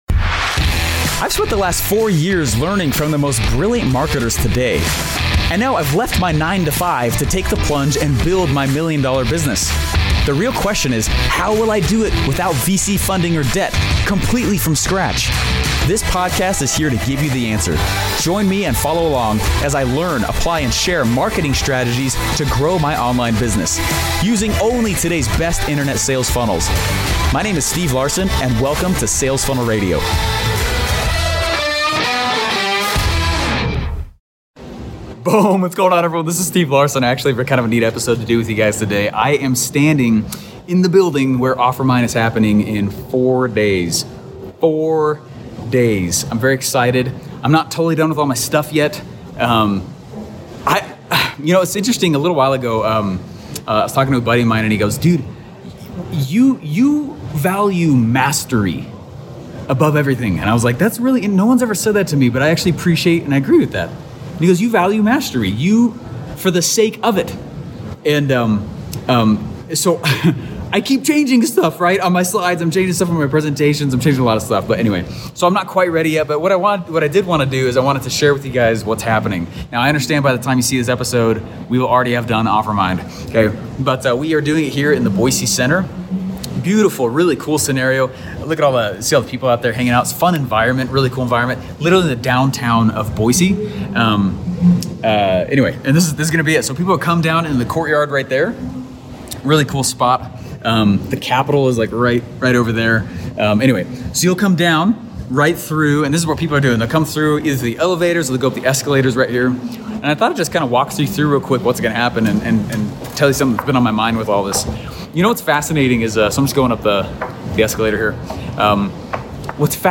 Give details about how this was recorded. Here's a sneak peek of the event hall, the week before OfferMind. I walk through some of the event psychology…